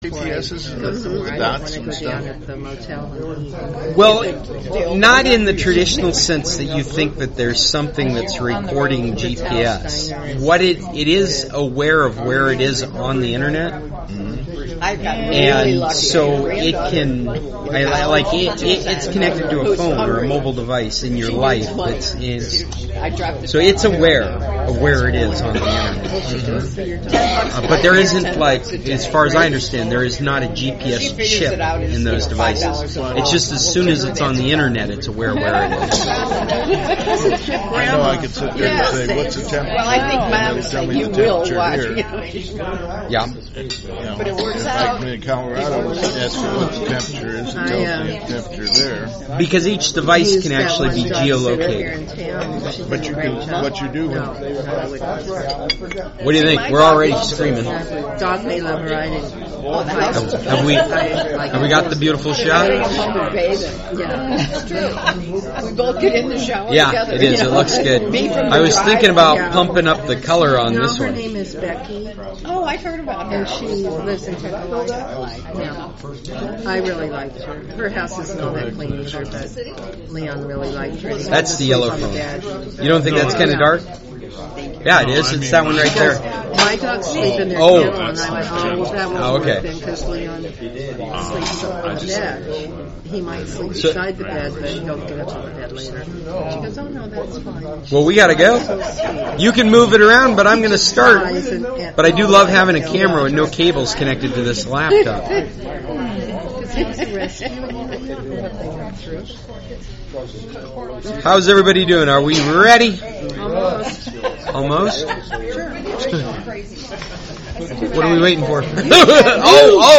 So I’ve had to post my local recording.